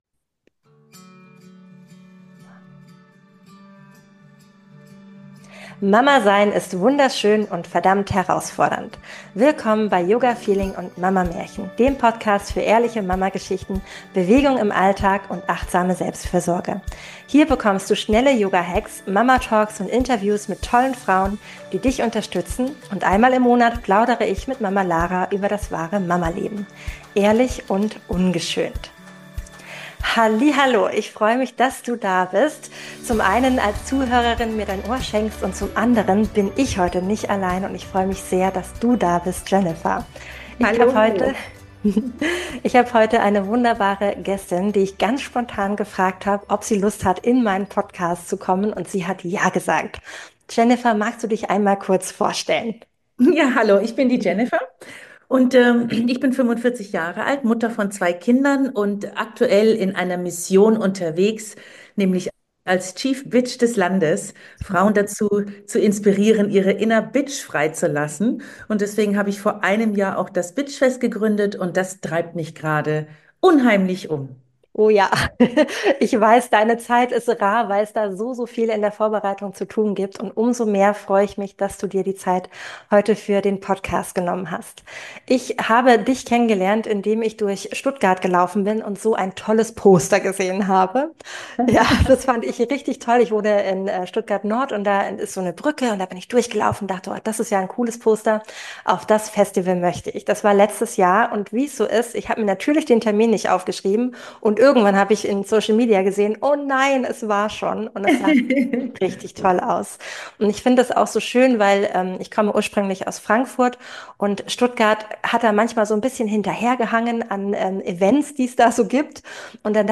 Ein ehrliches Gespräch